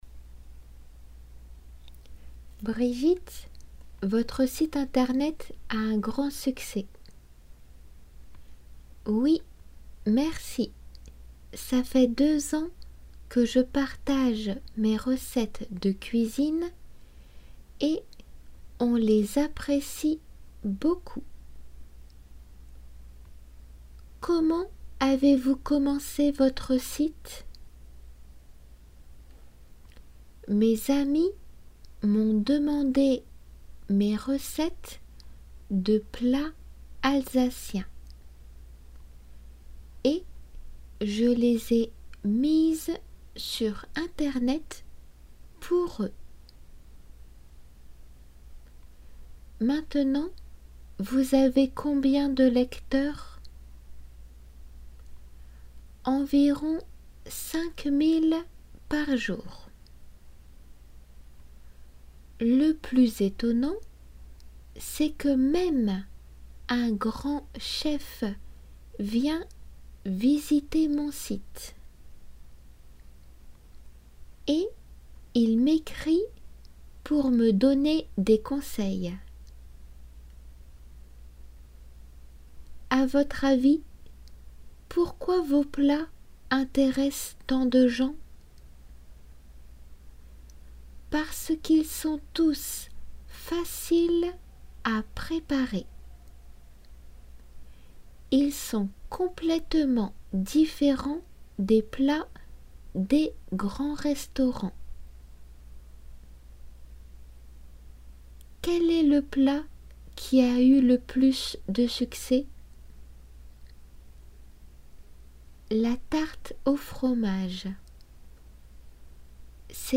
先ず読まれる本文の２人の会話をよく理解して聞けることです。